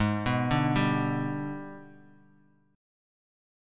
AbmM7b5 chord